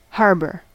Ääntäminen
US : IPA : [ˈhɑːr.bɜː]